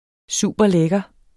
Udtale [ ˈsuˀbʌˈlεgʌ ]